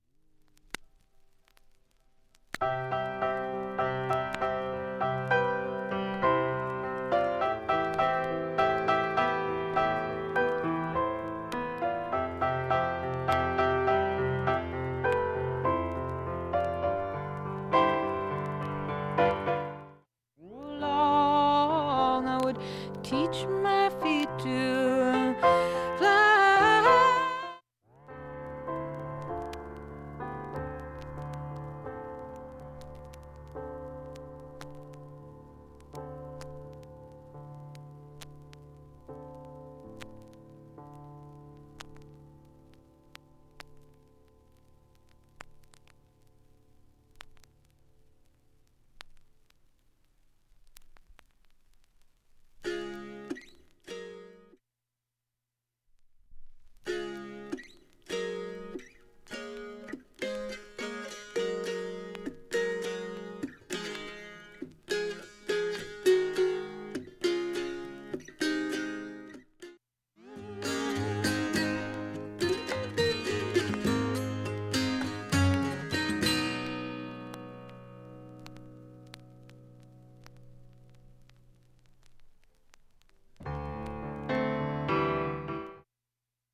音質良好全曲試聴済み。
にかすかなプツが１２回出ます。